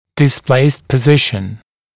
[dɪs’pleɪst pə’zɪʃn] [дис’плэйст пэ’зишн] смещённое положение